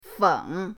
feng3.mp3